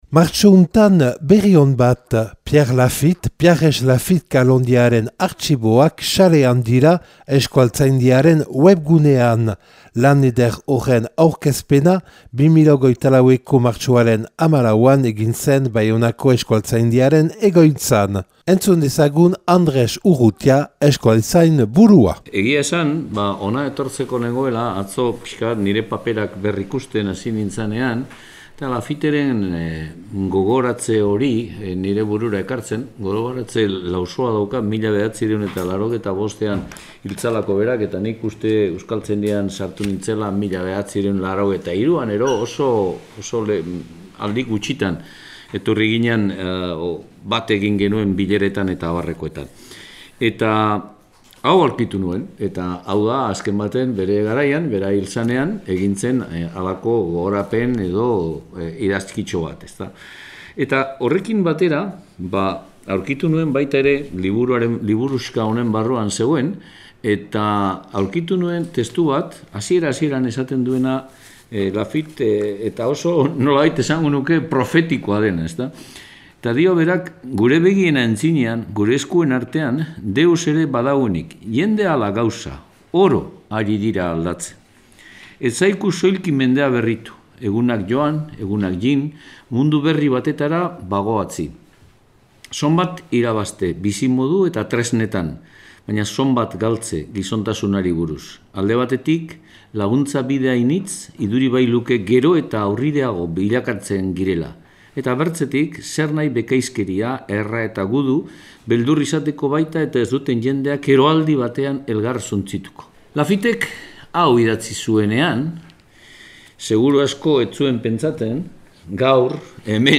Accueil \ Emissions \ Infos \ Elkarrizketak eta erreportaiak \ Euskaltzaindiak bere webgunean argitaratu du Piarres Lafitte kalonjearen (...)